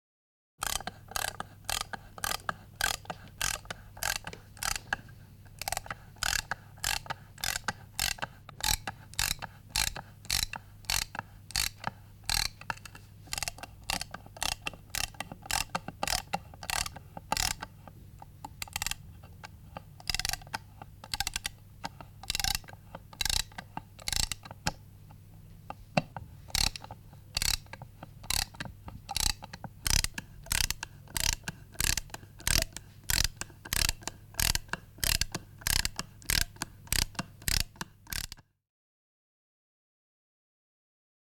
Звук ремонтного ключа трещотки